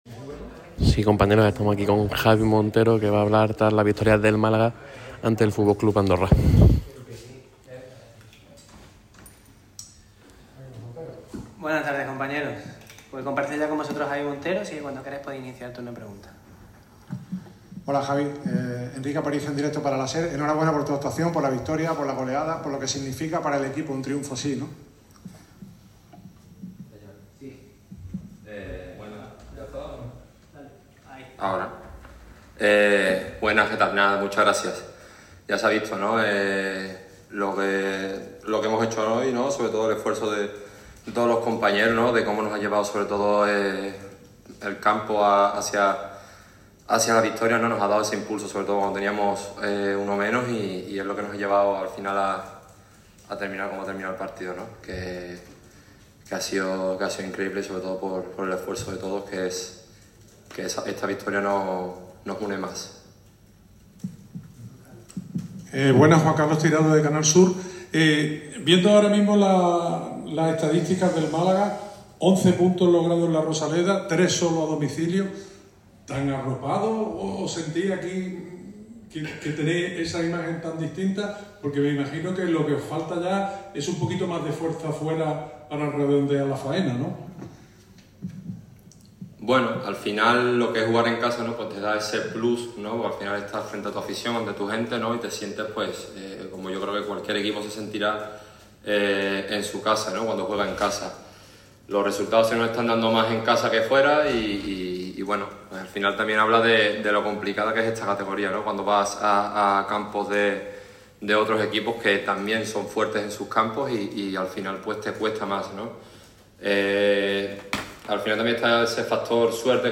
El defensa del Málaga CF, Javi Montero, ha comparecido ante los medios en la sala de prensa de La Rosaleda después de la goleada de los blanquiazules ante el FC Andorra.